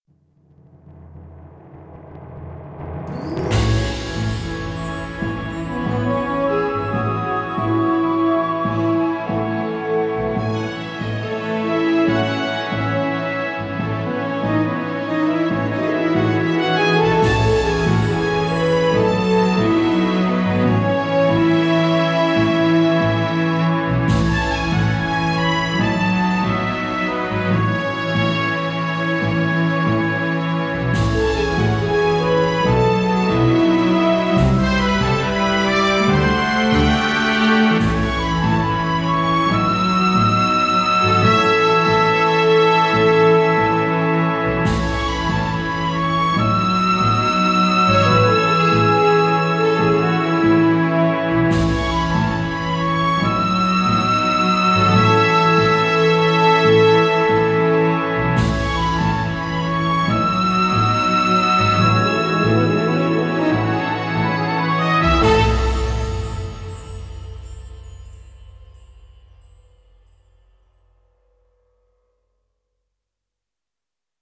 This is a short orchestral piece I wrote recently. I was inspired by soundtrack music although this wasn't written to be synced to film.